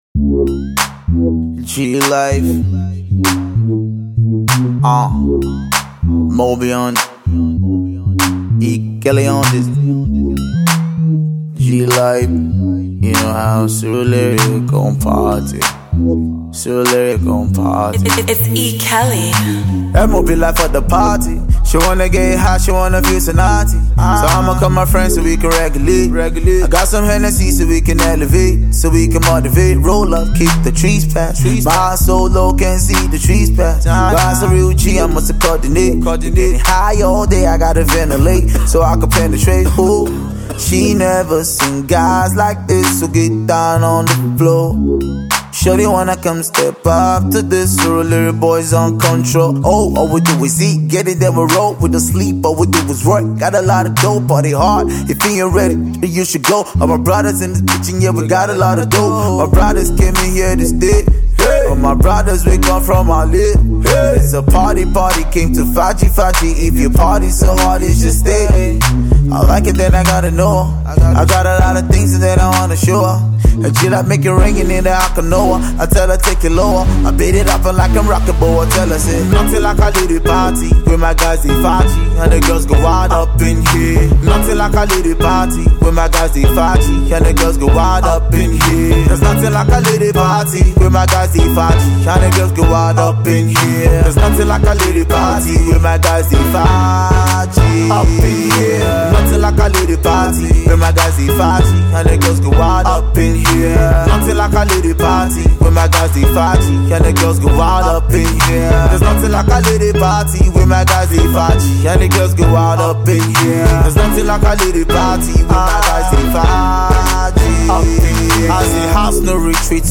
for the dance floor